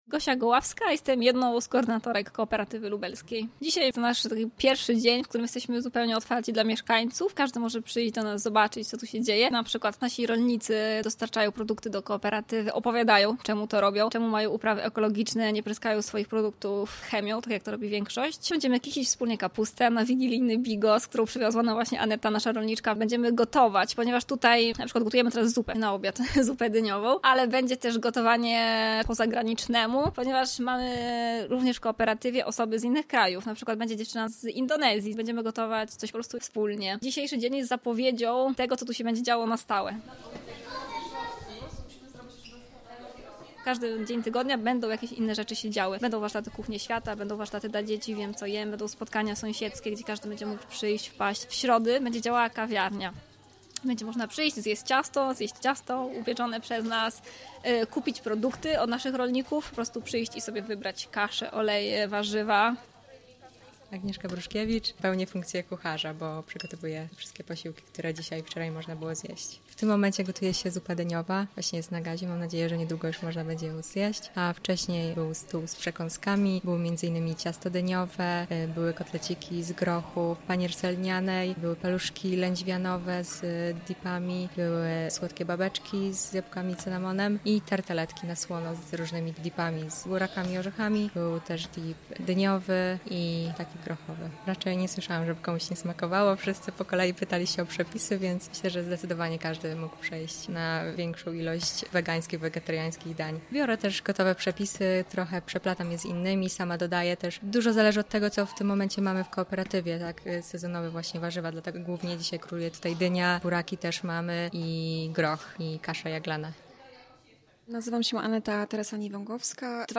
CU Wspólna otwarcie (obrazek)